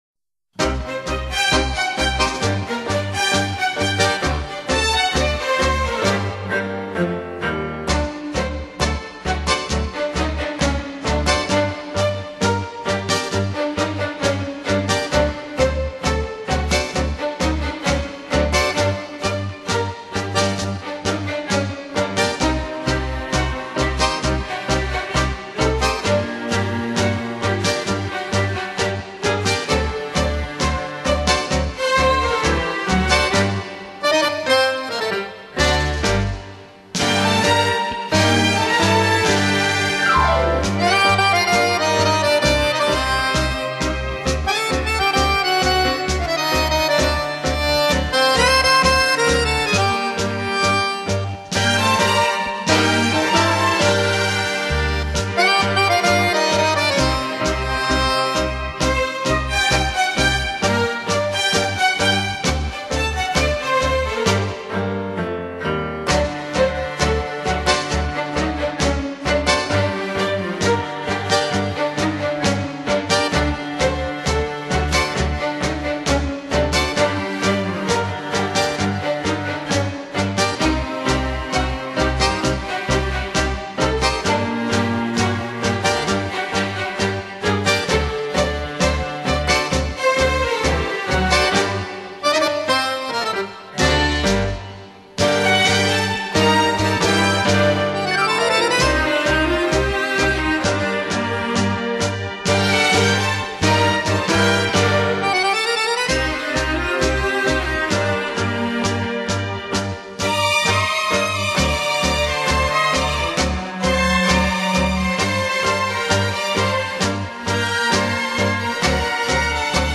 Genre: Instrumental, Easy Listening